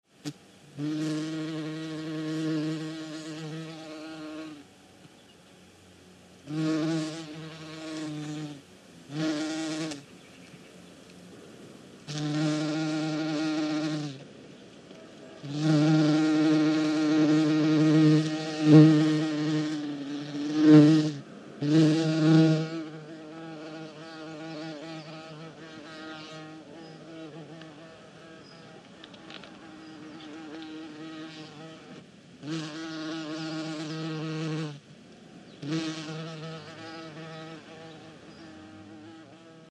BeeCUBuzzOnFlower PE660301
ANIMAL BUMBLE BEE: EXT: Close up buzzing from flower to flower, low level ambient rumble, distant intermittent birds in background.